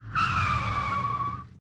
automobiles_drifting.ogg